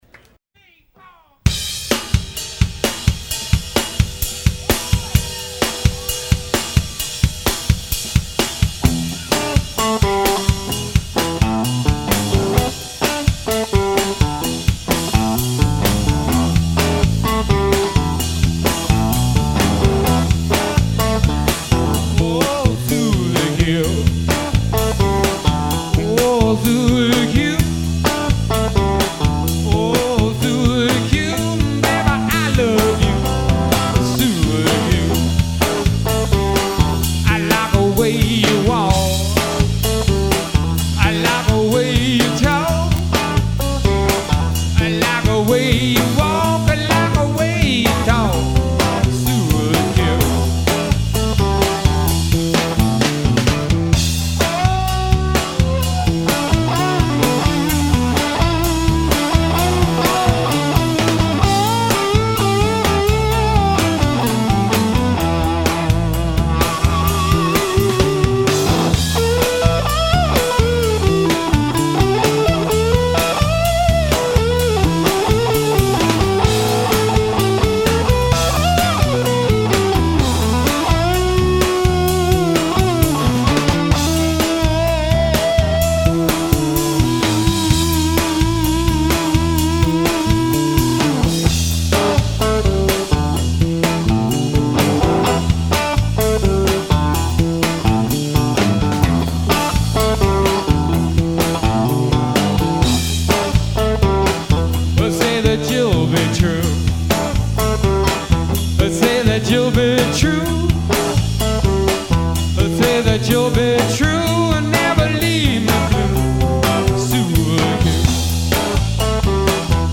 performing live at rehearsal in Burbank in 1997
hoodoo howling